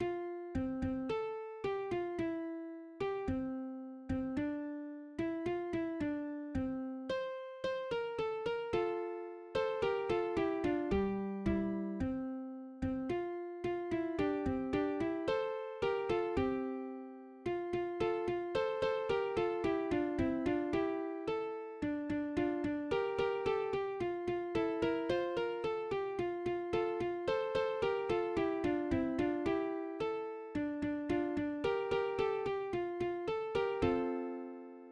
acoustic guitar (nylon)
} { f4 c4:7 f2 } } } stimmeEins = { \relative c' { \voiceOne \time 4/4 \tempo 4=110 \key f \major \set Staff.midiInstrument="oboe" f4 c8 c8 a'4 g8 f8